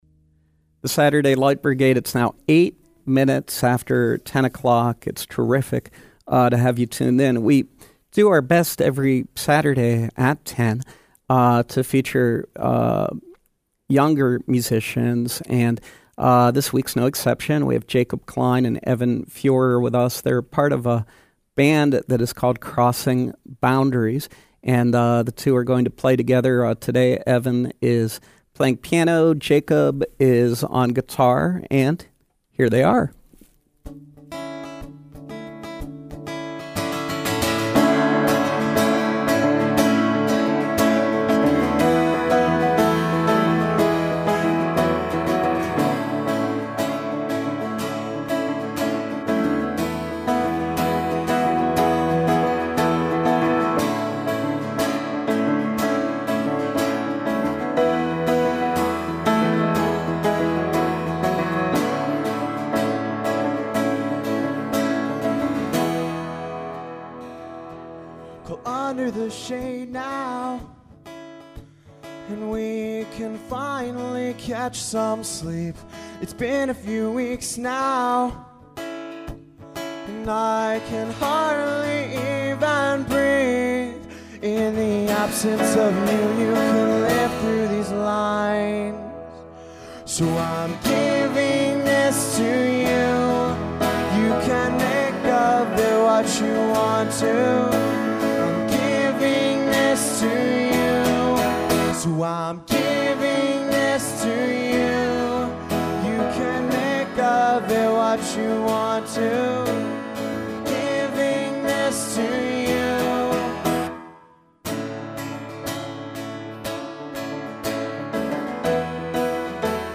eventually forming the up and coming pop/rock band
live for an acoustic set
guitar
piano